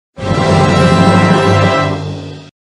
level_win.mp3